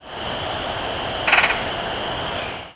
Red-headed Woodpecker
In short flights, gives a low, rattle (e.g., "ddrrp") and a husky, growling "jerra".
Rattle call from bird in short flight.